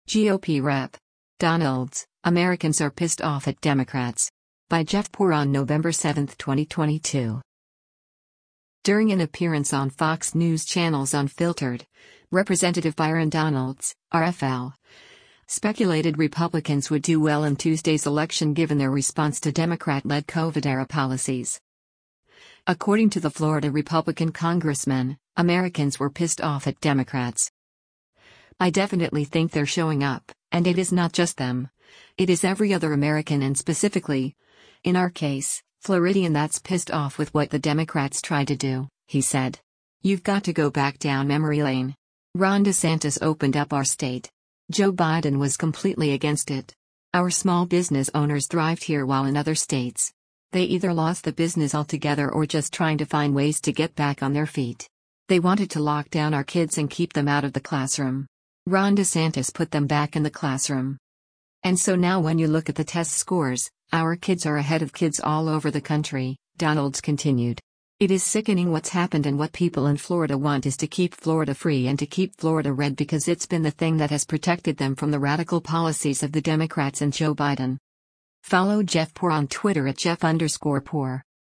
During an appearance on Fox News Channel’s “Unfiltered,” Rep. Byron Donalds (R-FL) speculated Republicans would do well in Tuesday’s election given their response to Democrat-led COVID-era policies.